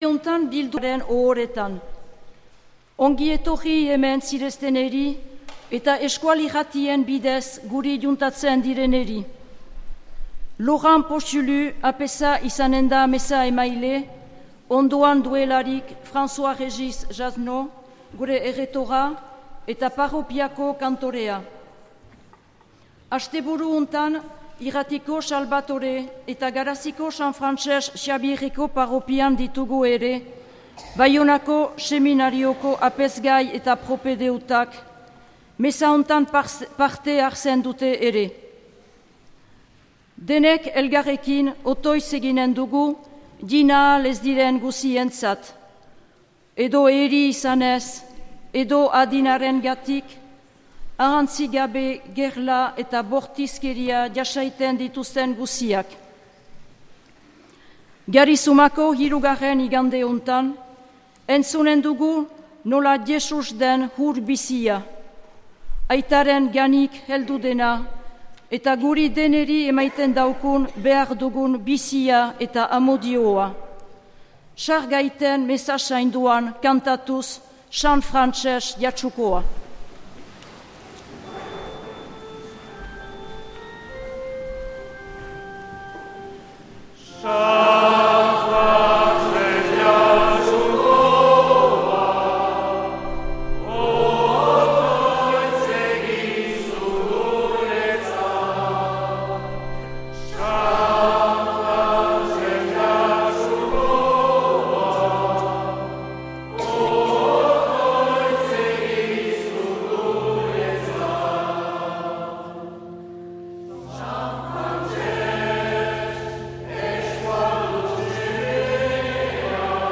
2026-03-08 Garizumako 3. Igandea A - Jatsu beila Xabiereko San Frantses ohoretan
Accueil \ Emissions \ Vie de l’Eglise \ Célébrer \ Igandetako Mezak Euskal irratietan \ 2026-03-08 Garizumako 3.